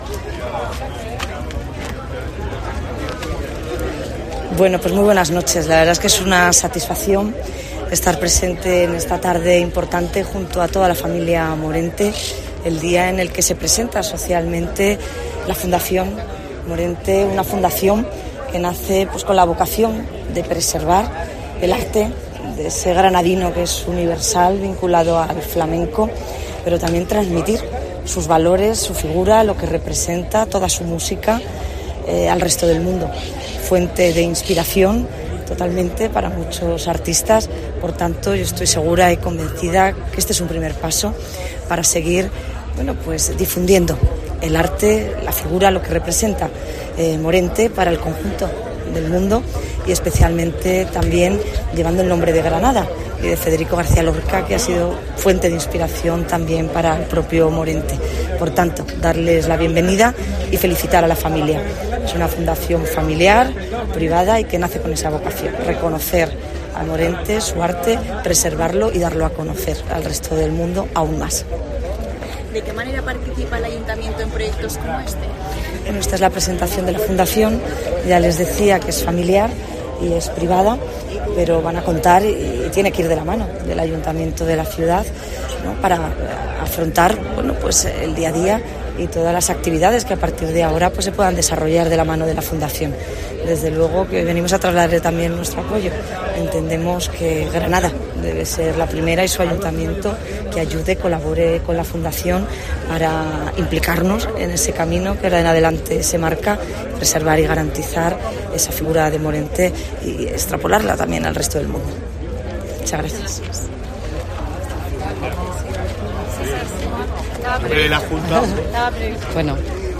Marifrán Carazo, alcaldesa de Granada y Rocío Díaz, consejera de Fomento